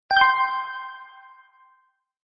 场景2主角获取奖励音效（更响耳）.mp3